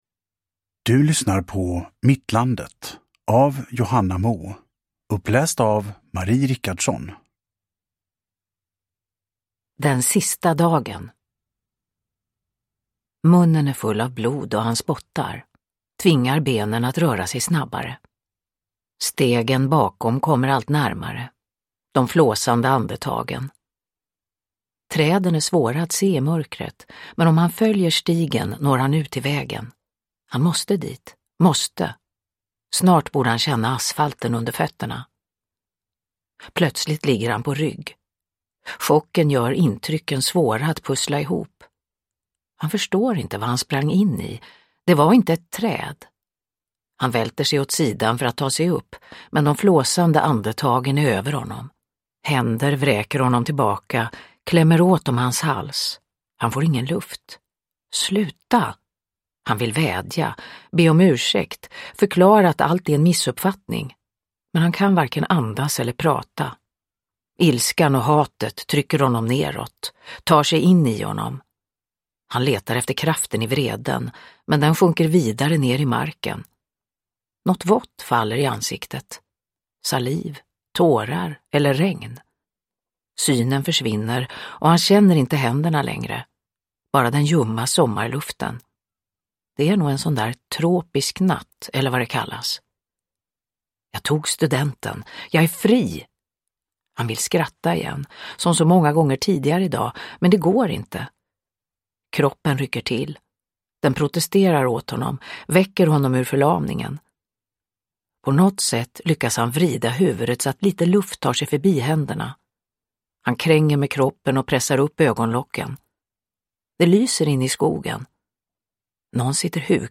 Uppläsare: Marie Richardson
Ljudbok